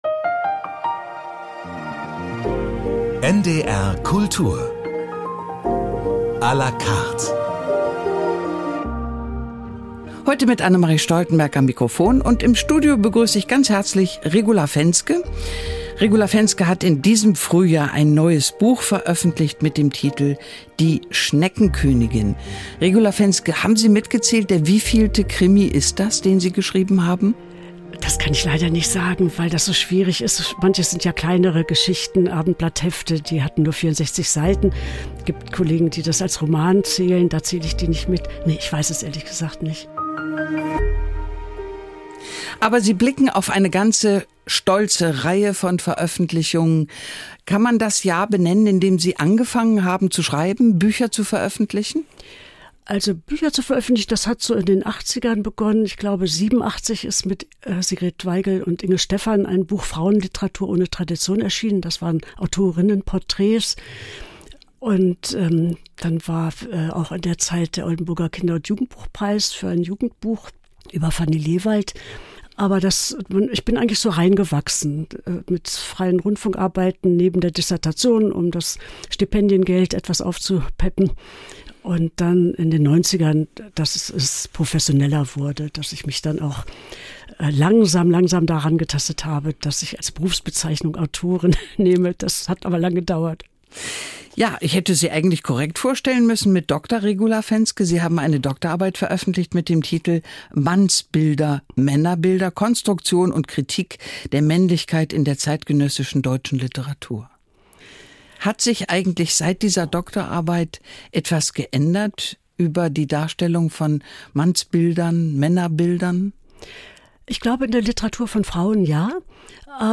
Die Sendung, in der Leute sprechen, über die man spricht: bekannte Persönlichkeiten aus Kunst, Kultur, Politik und Gesellschaft. Schauspieler, Musiker, Wissenschaftlerin oder Autorin – in "NDR Kultur à la carte" hören wir interessanten Menschen beim Erzählen zu.